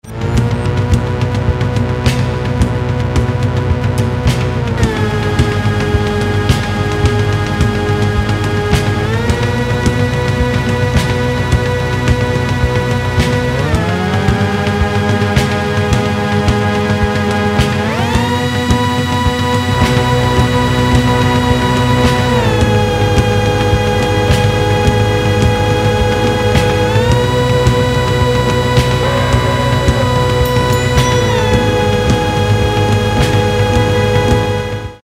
with added synth lines